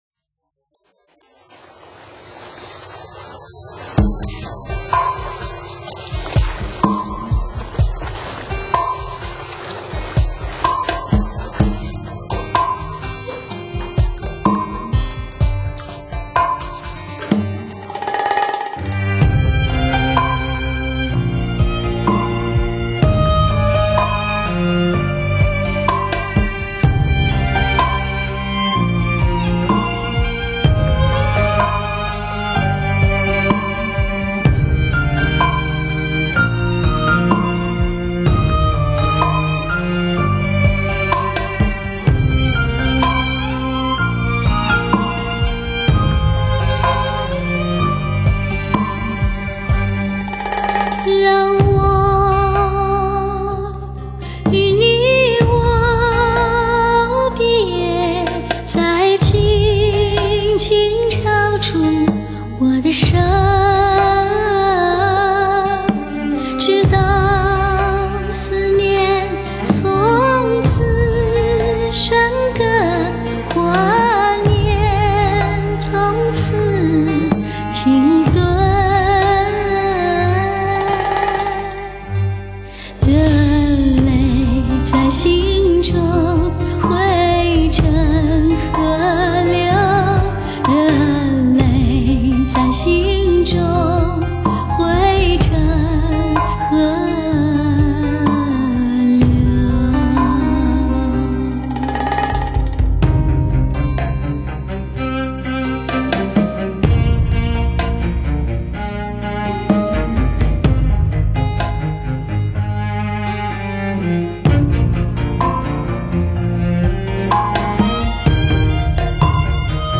抒情女声